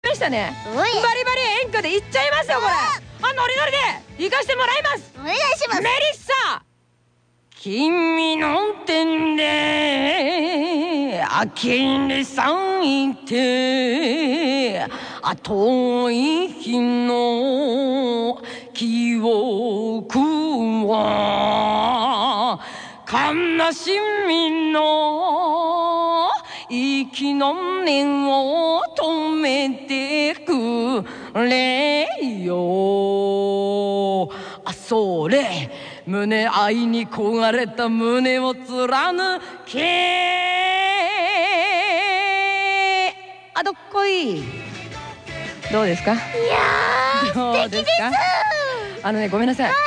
version a capella
doubleuse d'Edward